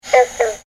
Green Frog - Lithobates clamitans
One call